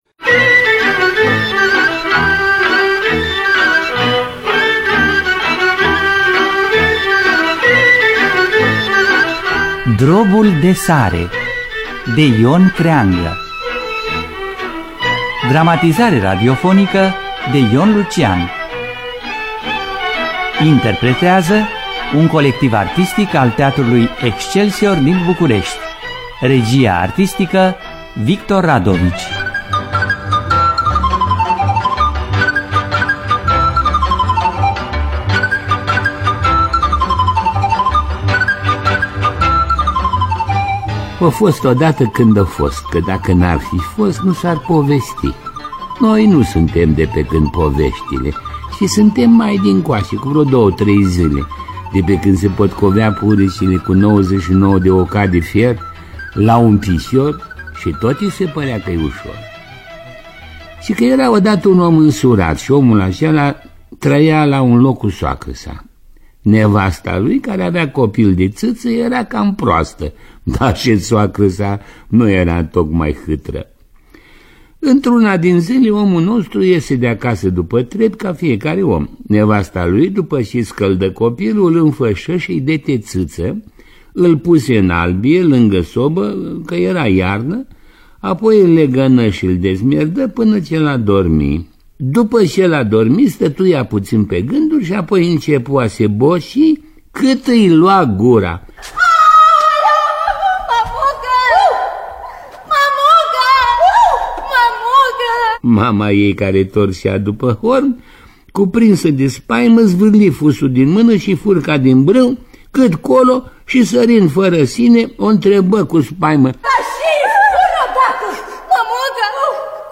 Dramatizarea radiofonică de Ion Lucian.